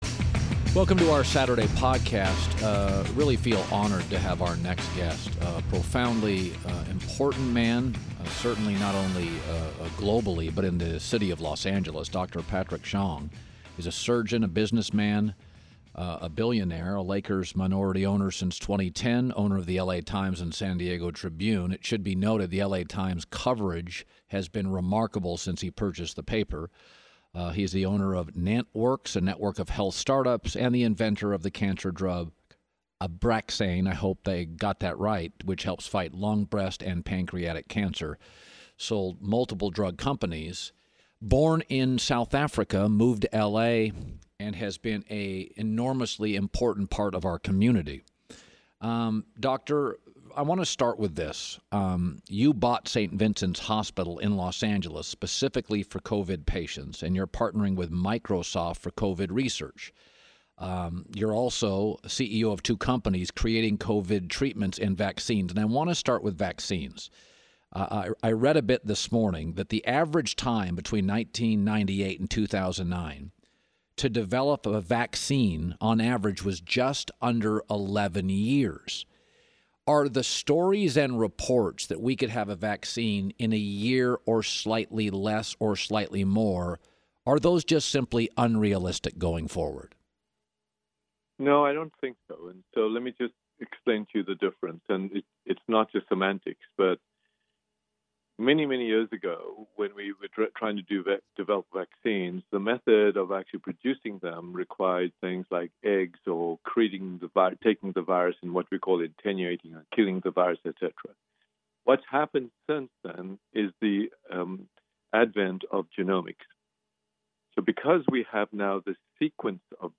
A selection of three essential articles read aloud from the latest issue of The Economist. This week, has covid-19 killed globalisation?
Zanny Minton Beddoes hosts.